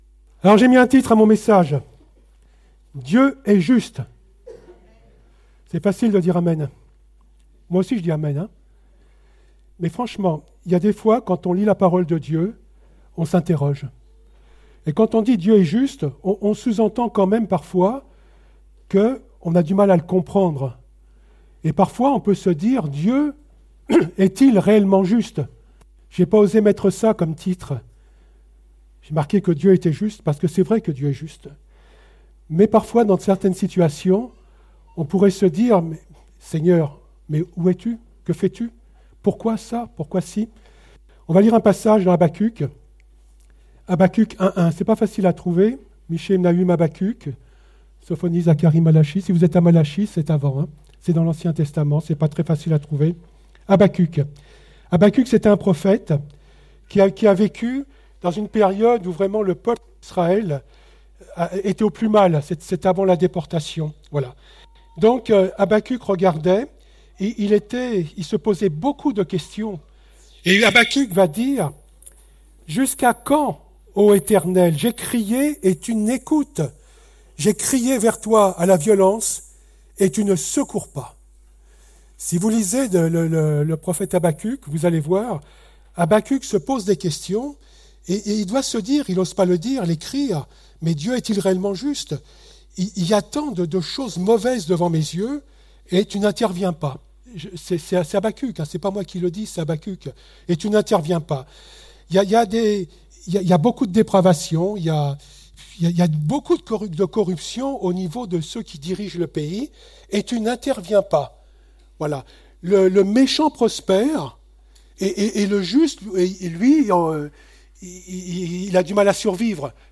Date : 25 février 2018 (Culte Dominical)